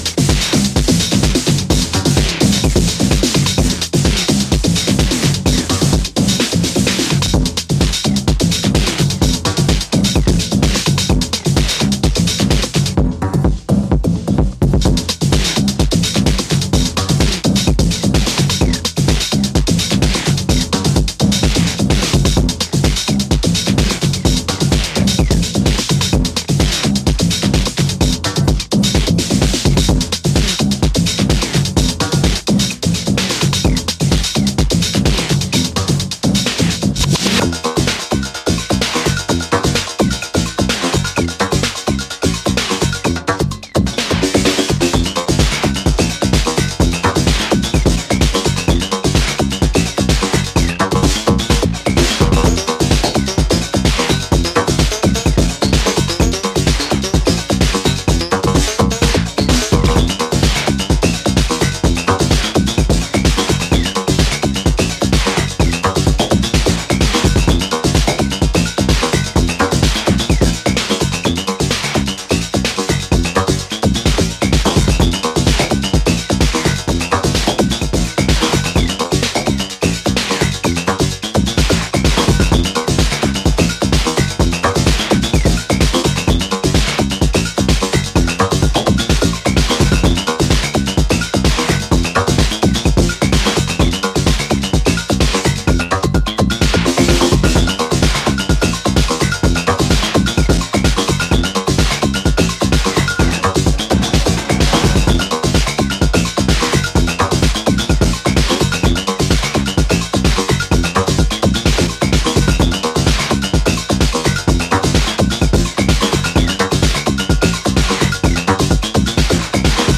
drum and bass